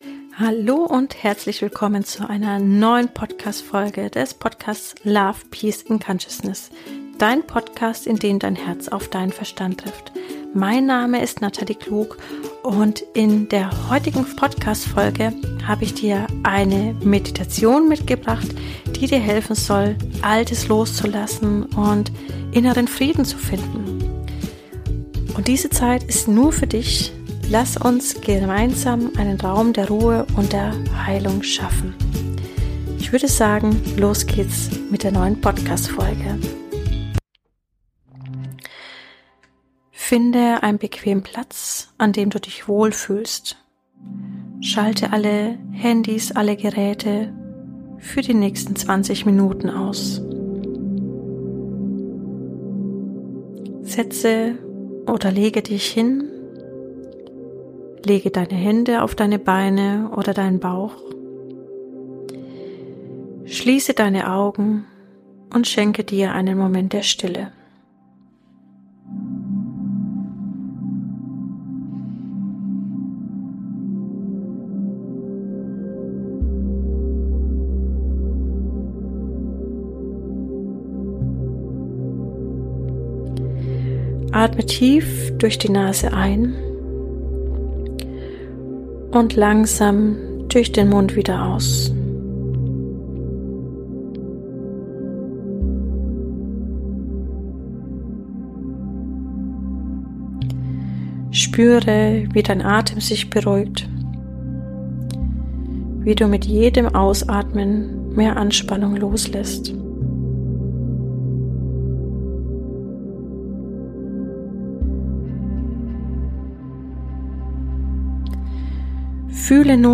Gemeinsam schaffen wir Raum für Klarheit, lassen alte Lasten los und empfangen goldenes Licht in deinem Inneren. Diese Meditation hilft dir, Stress abzubauen, alte Muster zu lösen und Platz für Neues zu schaffen.